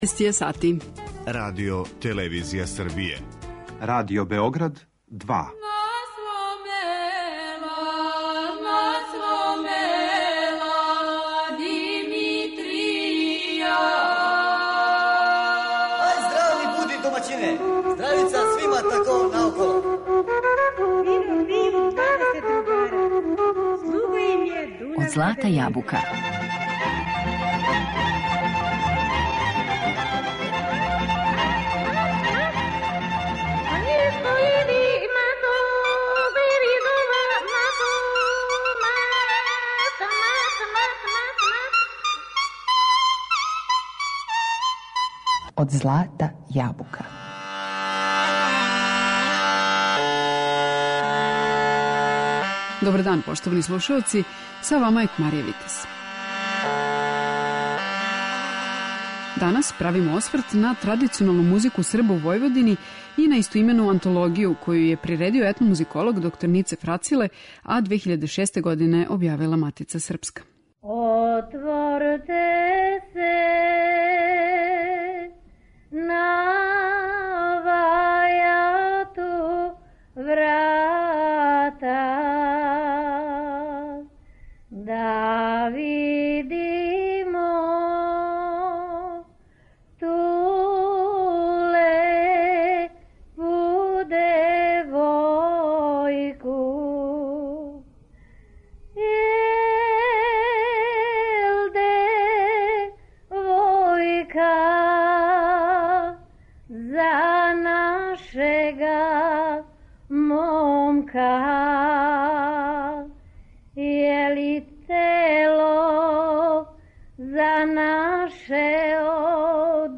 Традиционална музика Срба у Војводини
Ово вредно аудио издање, лепог дизајна и информативне пропратне књижице базирано је на теренским истраживањима која су се одвијала у периоду од 1979 - 2005. године. Тада је сакупљен богат материјал, више од хиљаду снимака међу којима је одабрано четрдесет репрезентативних примера вокалне и у мањој мери вокално-инструменталне праксе Срба у Војводини.